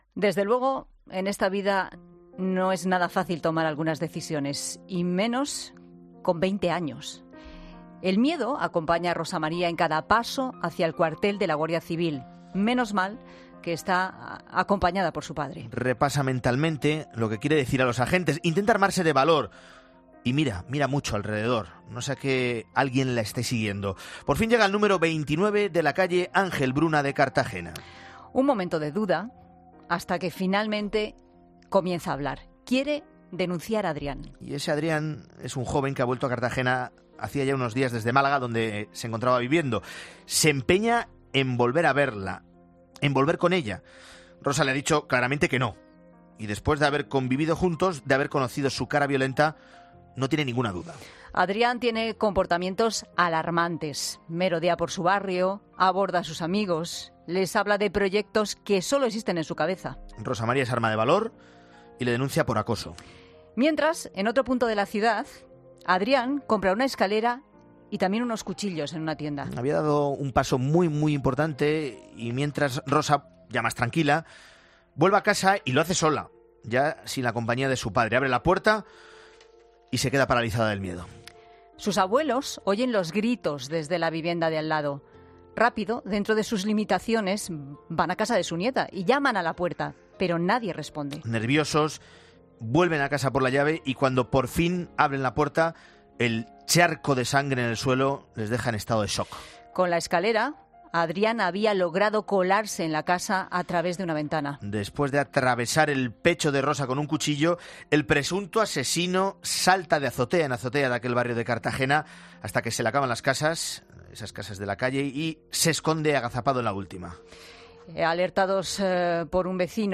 El concejal de Igualdad de Cartagena, David Martínez, ha lamentado y condenado enérgicamente en 'Mediodía COPE' este suceso y ha apostado por el trabajo conjunto de todos los agentes sociales "para terminar juntos" con la violencia machista.